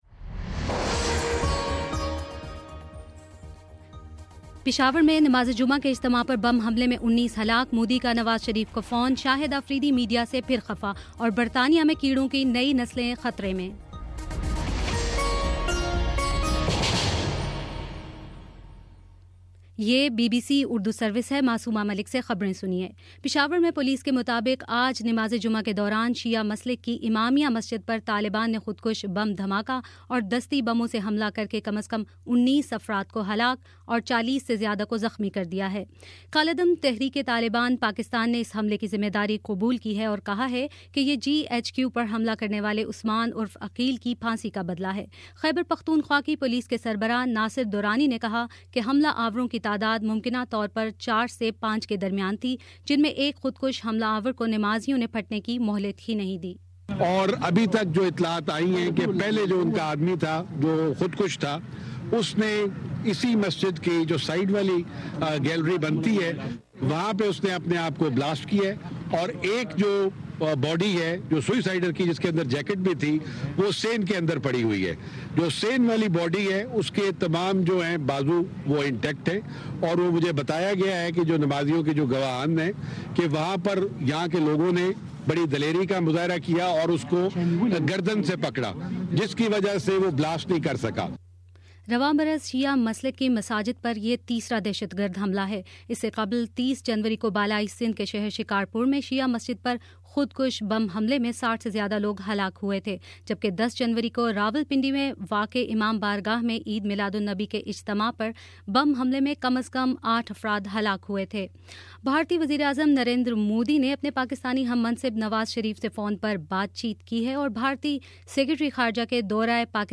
فروری 13: شام چھ بجے کا نیوز بُلیٹن